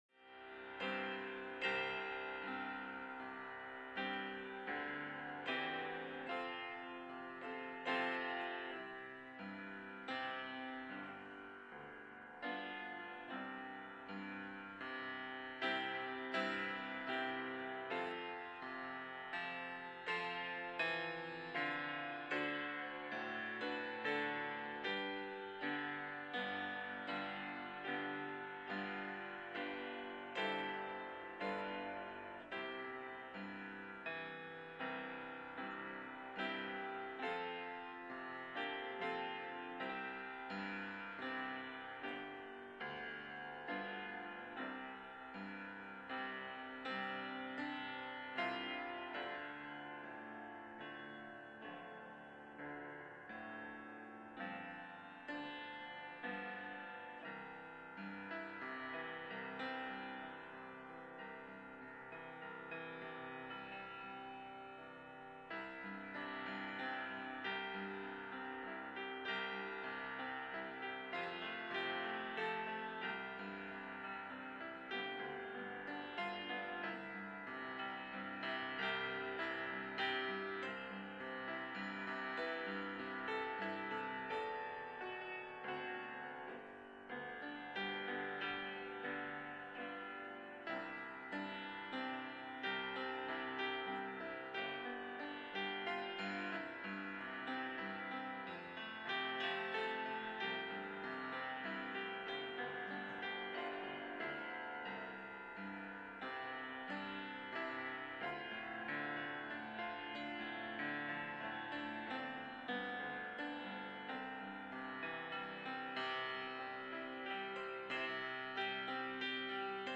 2-12-23-Sermon.mp3